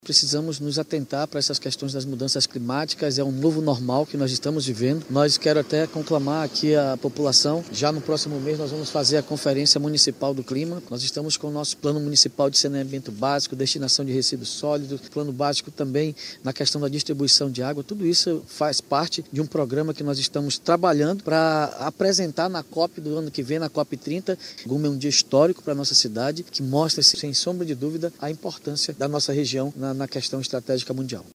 O prefeito de Manaus, David Almeida (Avante), anunciou, durante a chegada do chefe de Estado norte-americano, que a Conferência Municipal do Clima será realizada em dezembro, preparando a cidade para a participação na COP30, que ocorrerá em Belém.